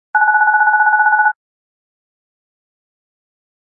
phone-ring.mp3